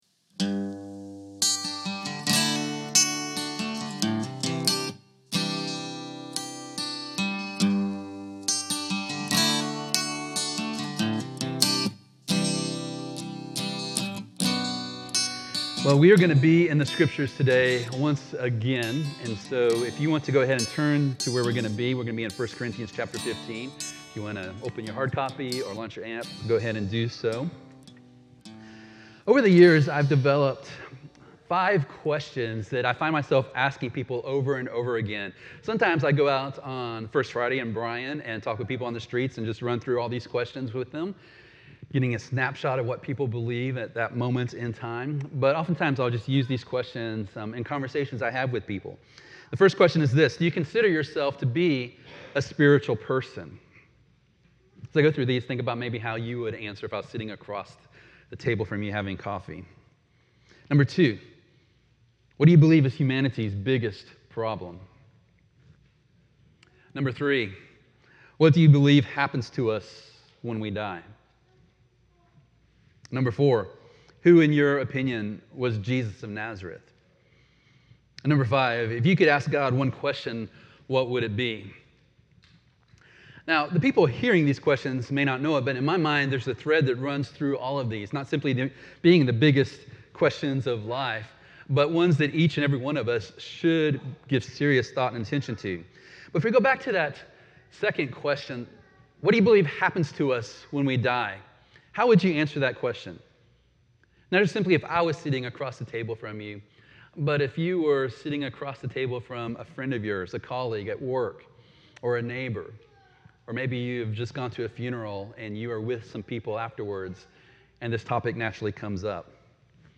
Sermon Audio Slide Deck Reflection Questions